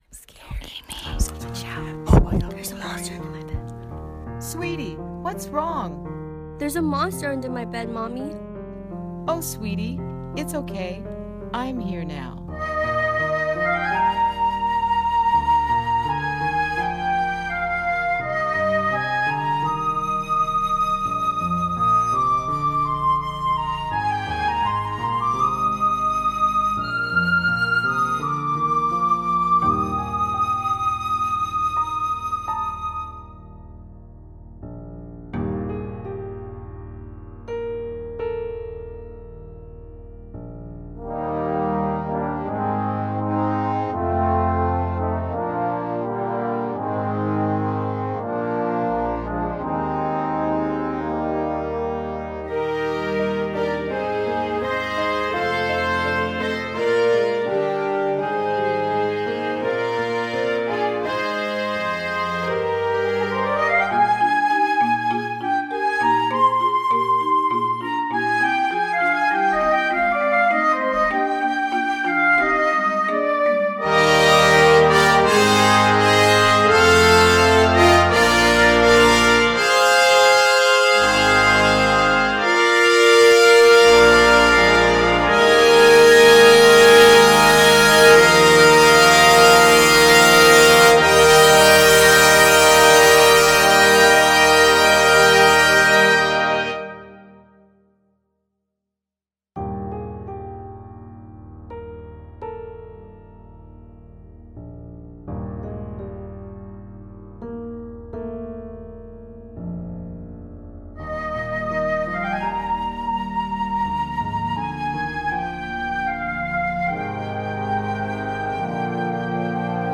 Winds
Percussion